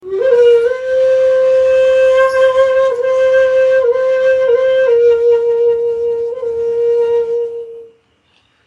Shakuhachi 53